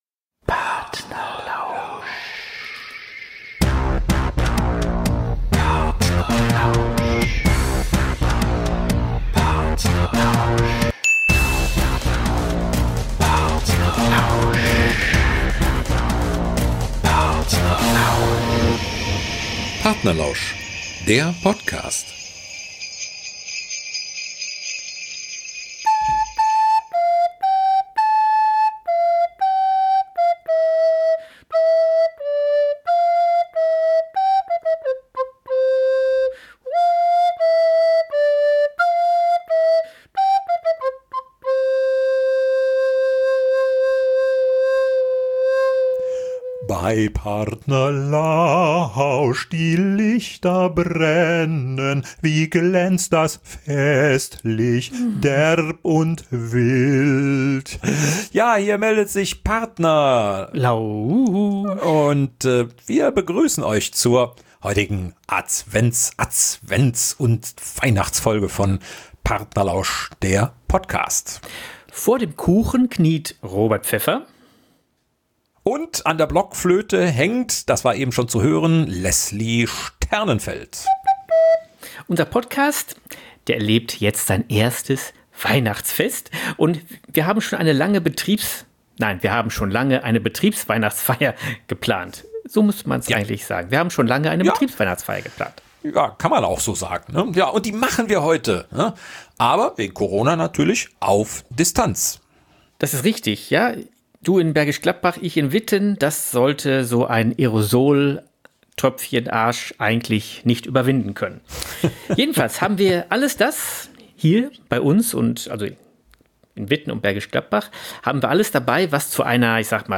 Unser Podcast über das Schreiben und Vortragen von Geschichten. Jede Folge mit einem Schwerpunkt und einer kompletten Story in der Mitte!